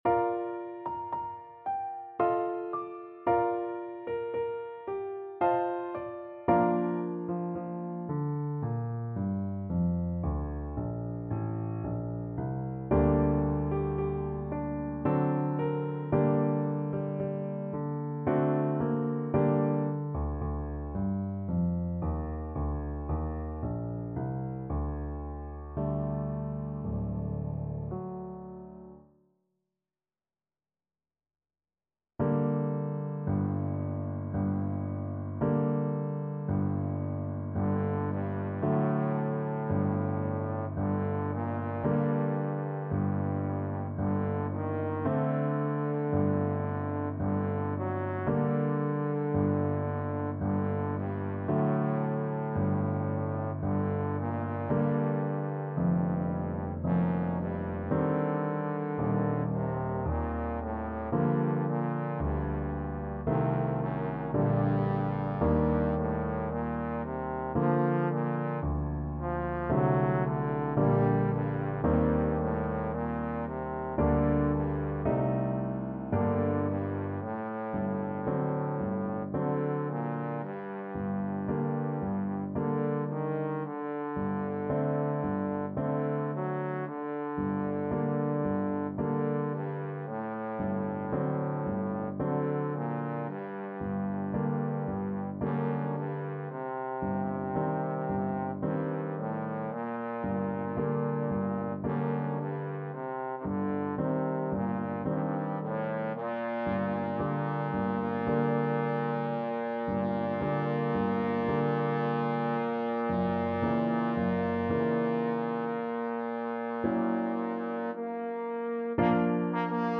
Trombone
3/4 (View more 3/4 Music)
~ = 56 Andante
Bb major (Sounding Pitch) (View more Bb major Music for Trombone )
F3-G5
Classical (View more Classical Trombone Music)
tchaik_serenade_melancolique_TBNE.mp3